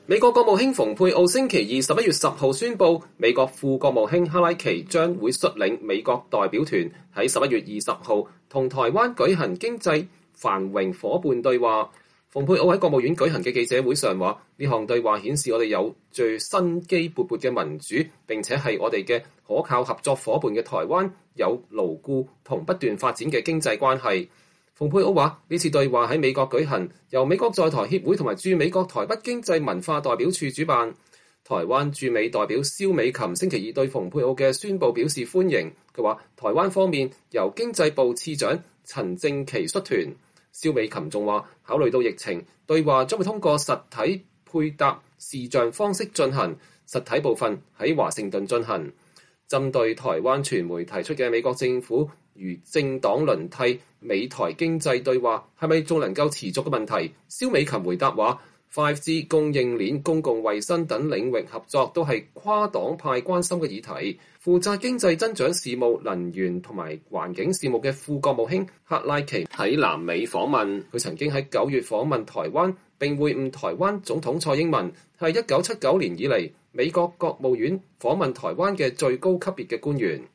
蓬佩奧國務卿在國務院的記者會上講話。(2020年11月10日)